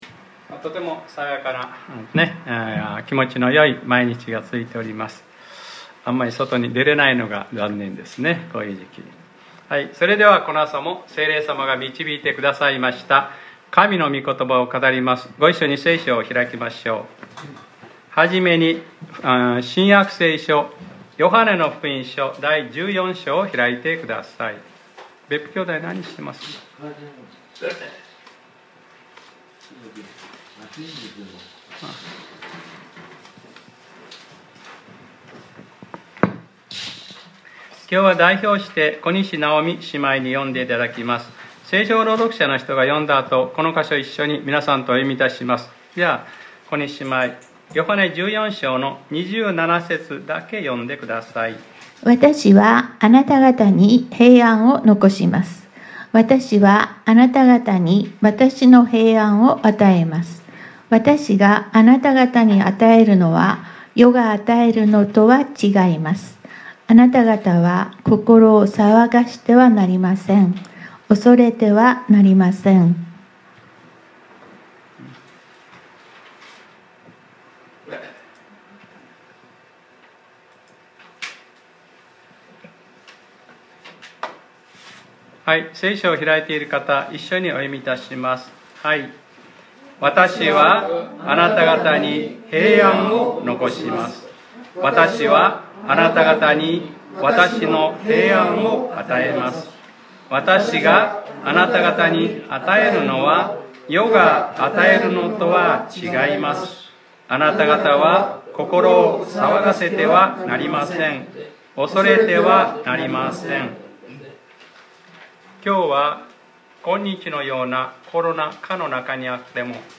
2022年01月16日（日）礼拝説教『 万人祭司：愛すること 』